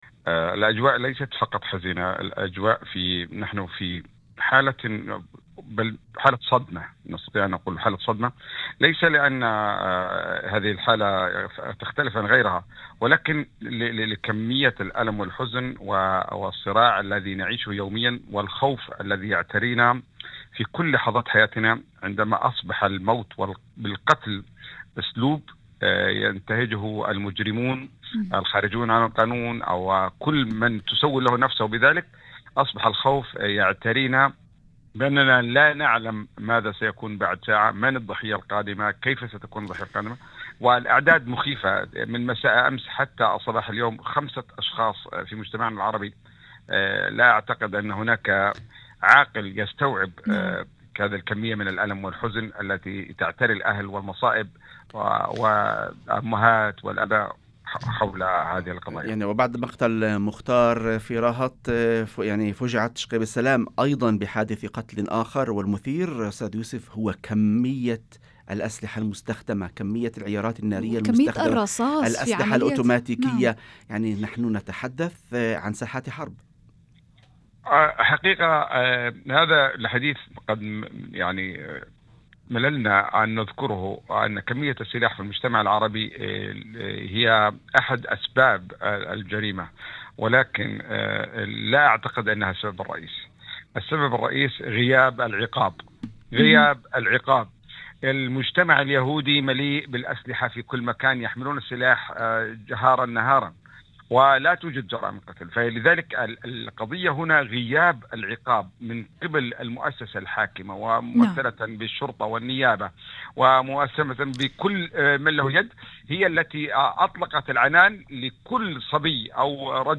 وأضاف في مداخلة هاتفية ضمن برنامج "يوم جديد"، على إذاعة الشمس: